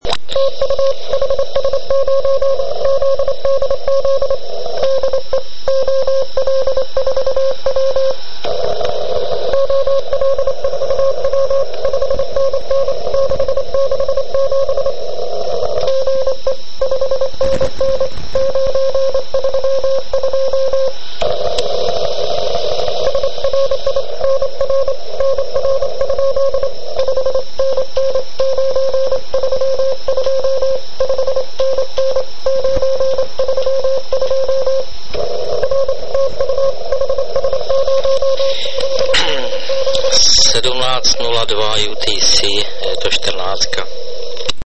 Záznam spojení 14 MHz.